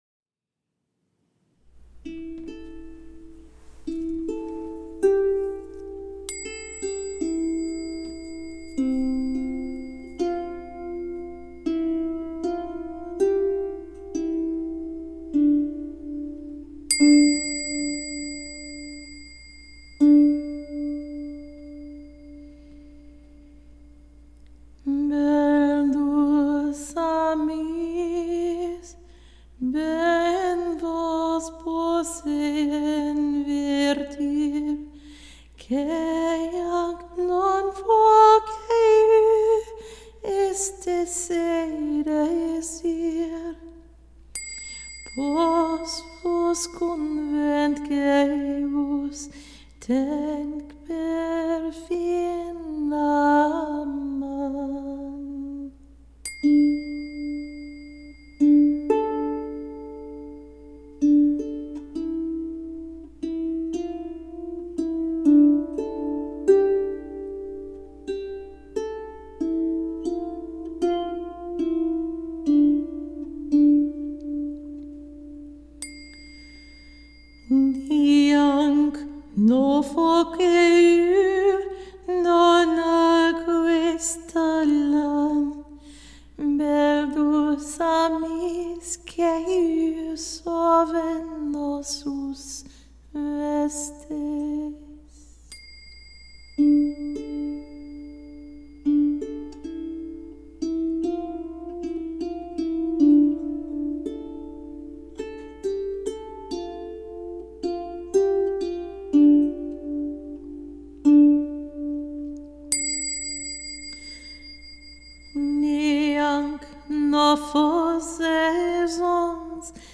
My pronunciation of the medieval Occitan (the pronunciation of which is very similar to Spanish) is likely a bit historically sketchy. The modes are ancient, but the tune is ahistorical.
It takes the poetic segments A, B, C which, for my version, become A, B, C, B, A.